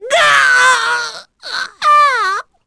Lakrak-Vox_Dead_b.wav